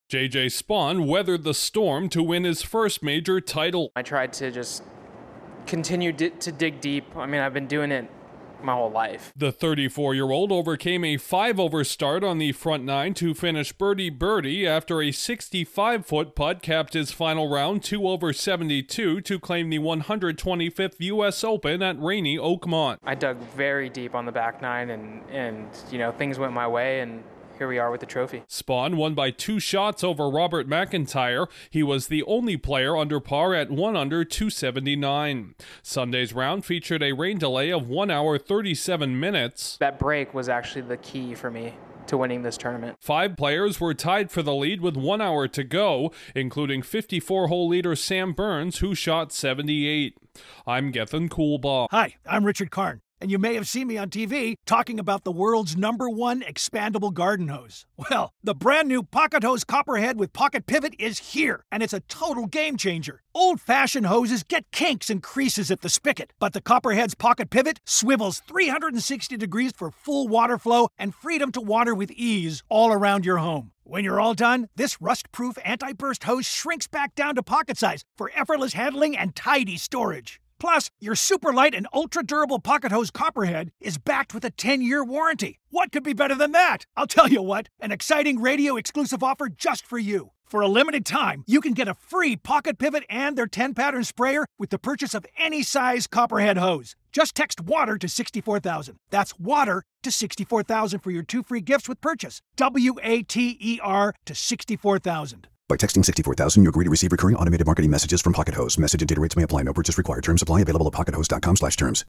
Spaun navigated the worst of wet Oakmont to win the U.S. Open. Correspondent